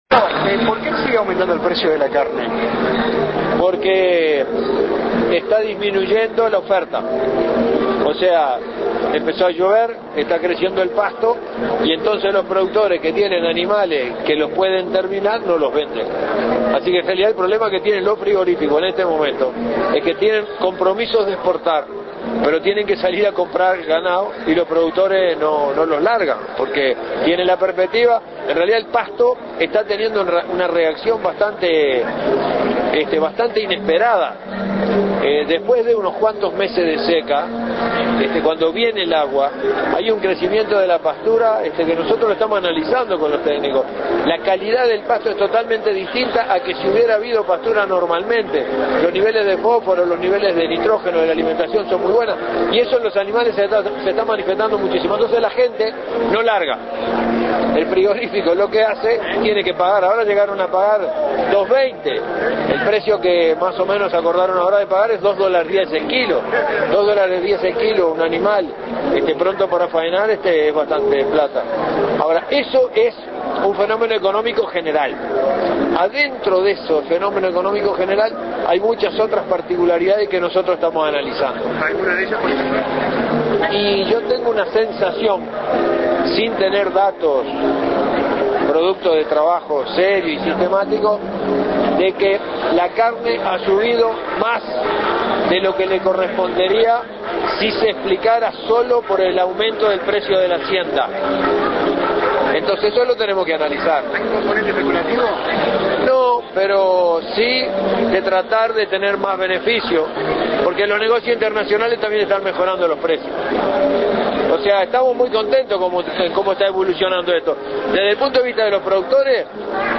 Previo al comienzo del balance de la gesti�n que realiz� el Presidente de la Rep�blica, Tabar� V�zquez, en la Plaza Libertad, el Ministro de Ganader�a Agricultura y Pesca, Ernesto Agazzi, se�al� que actualmente su Cartera trabaja intensamente en la siembra, ya que el medio rural tiene mucha actividad en esta �poca del a�o. Adem�s, dijo que la naturaleza colabora, no s�lo con la lluvia, sino con los componentes que permitieron mantener la calidad de los suelos.